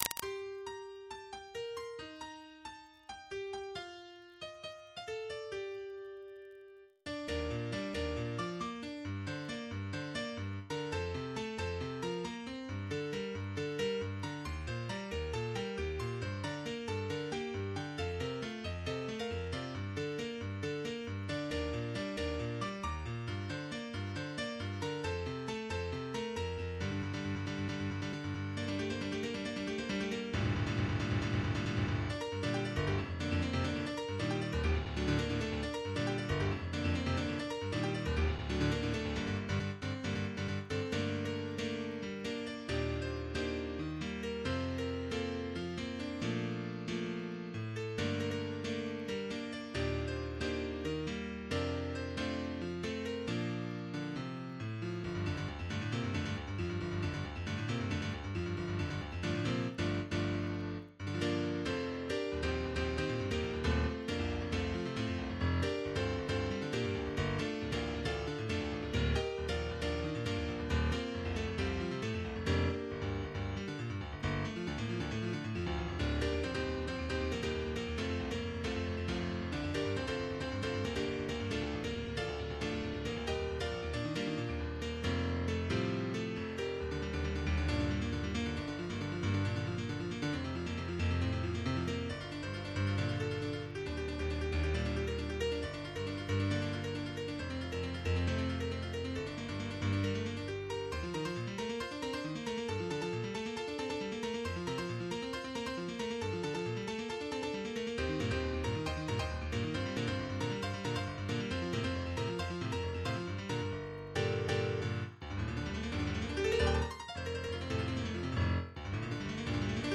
MIDI 53.64 KB MP3